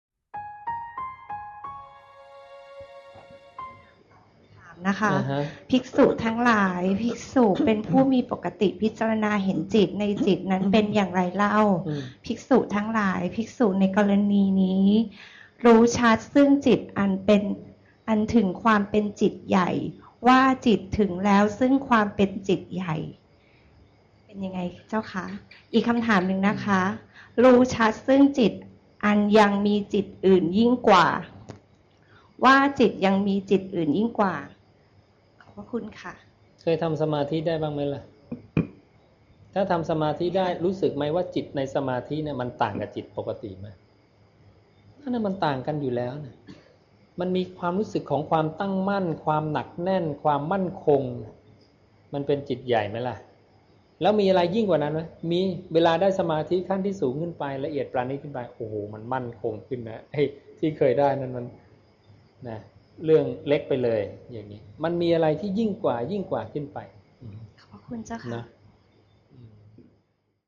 วัดนาป่าพง ลำลูกกา คลอง ๑๐ ปทุมธานี